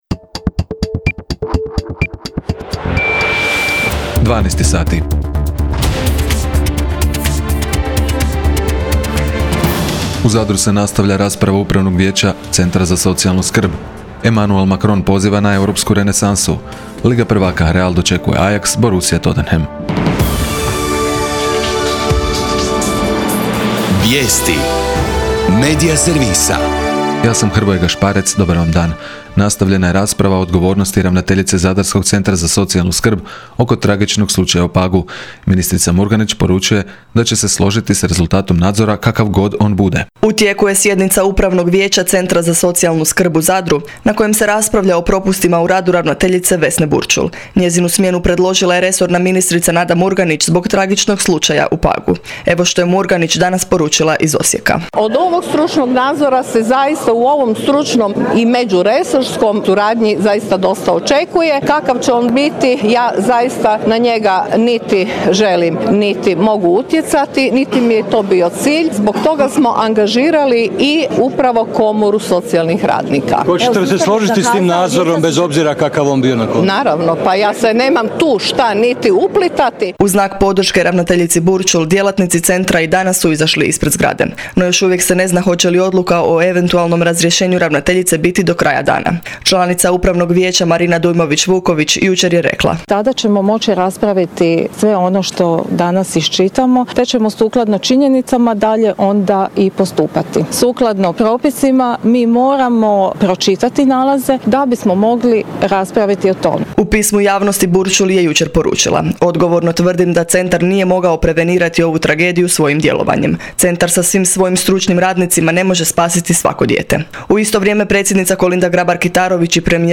VIJESTI U PODNE